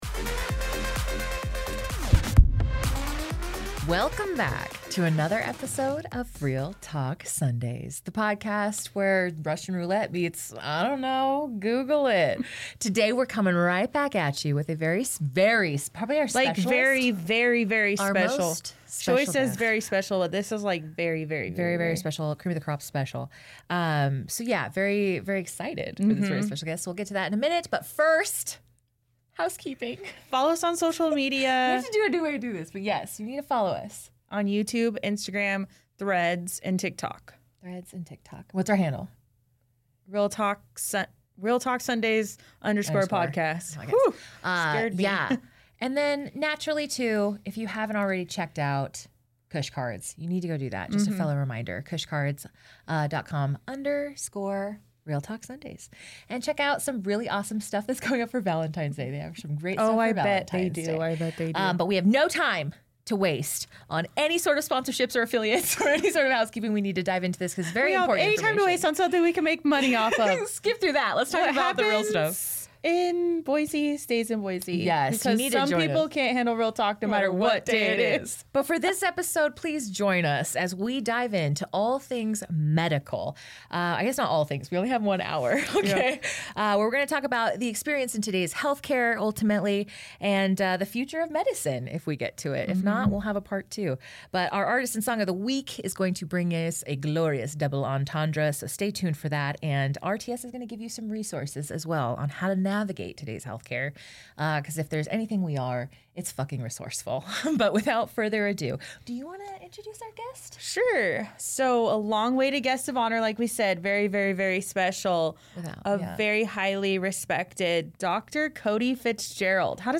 We also cover topics like the impact of health insurance, the ethics of pharmaceutical practices, and the evolving landscape of healthcare for future generations. Join us for a candid conversation that blends professional expertise with real-world experiences.